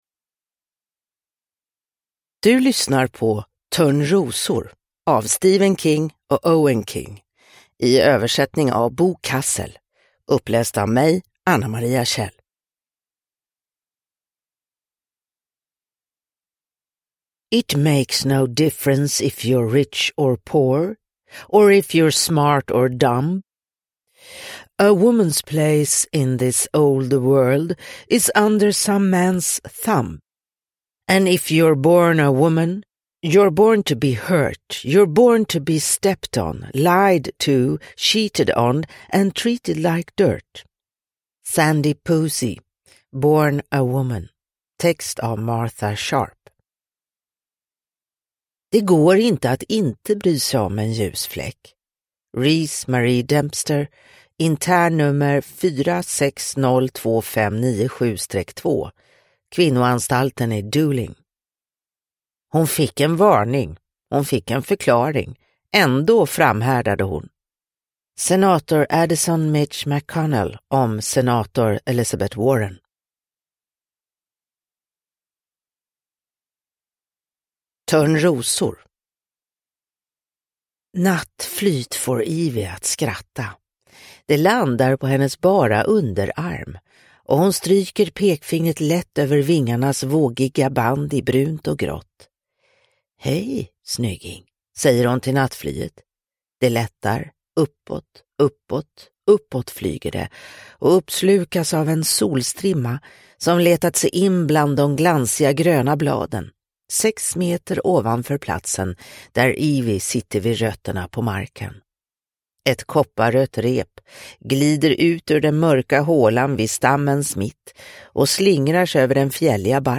Törnrosor – Ljudbok – Laddas ner